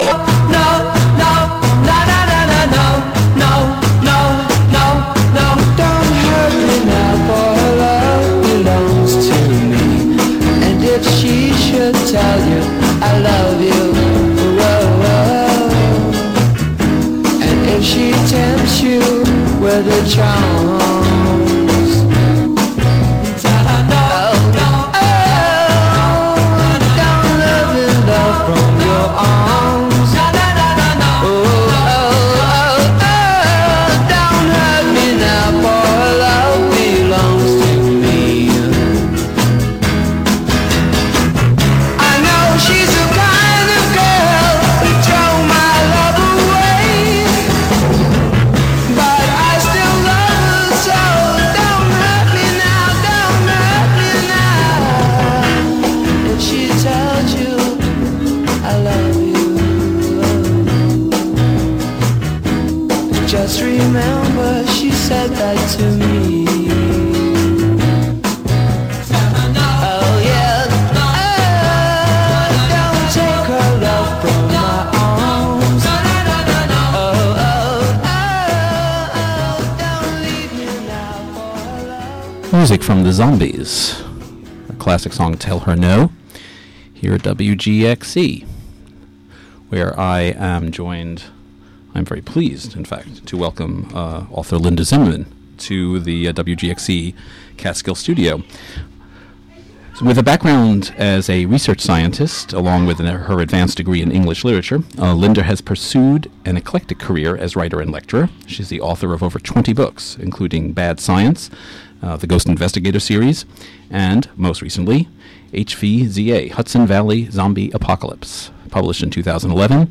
Interview
From "WGXC Afternoon Show" at the Catskill Community Center.